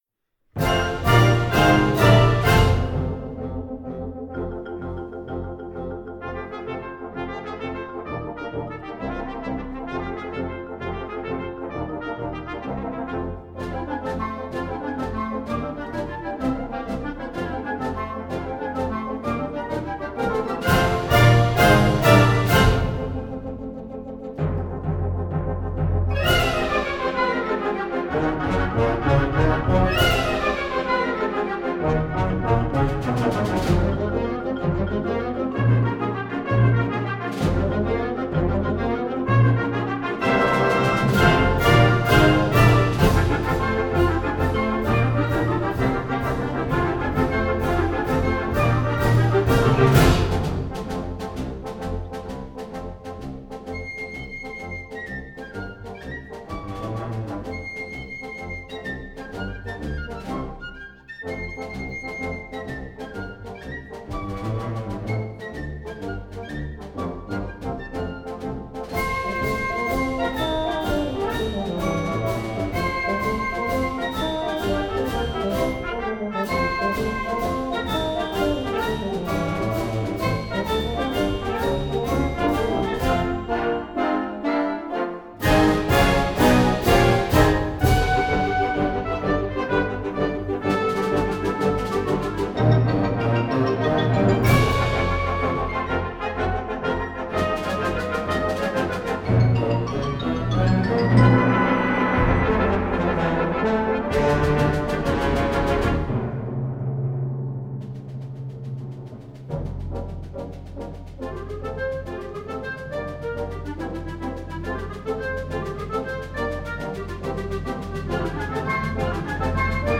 Gattung: Konzertwerk für Blasorchester
Besetzung: Blasorchester